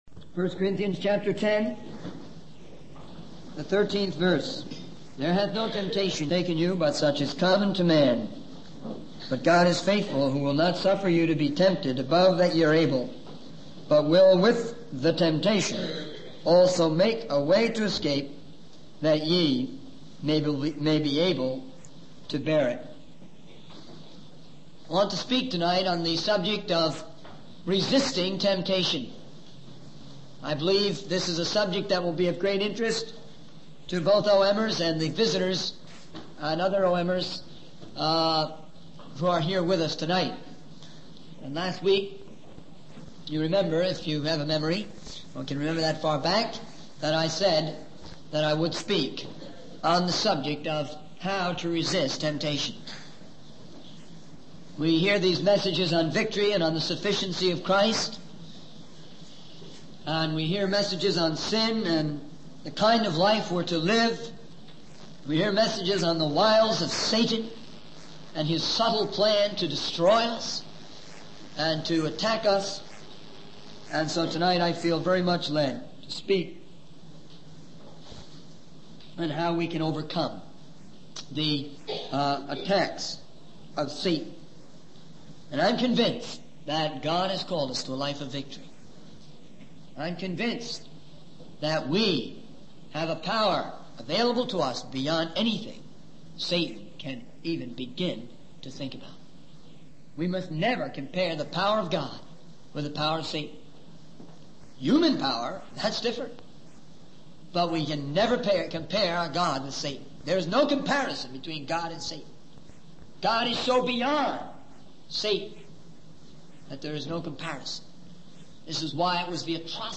In this sermon, the speaker emphasizes the importance of having faith and putting on the shield of faith to protect against the attacks of the devil.